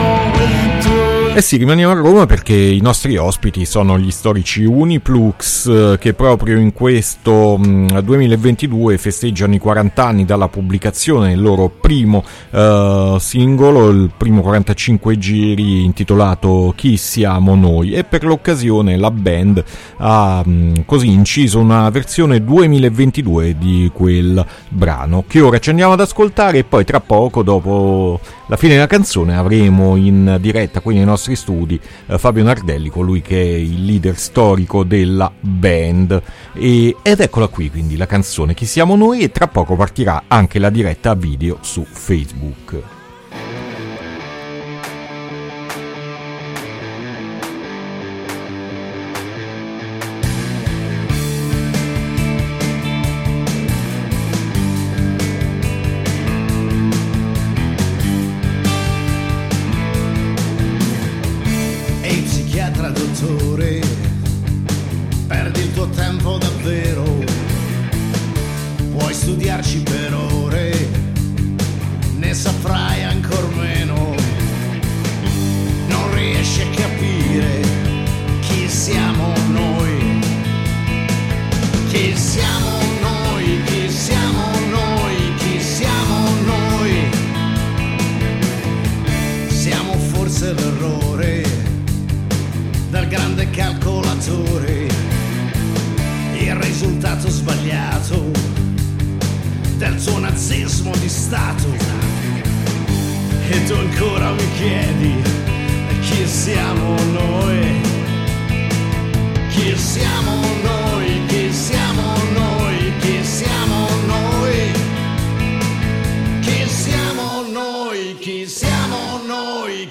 Intervista Uniplux ad Alternitalia 4-3-2022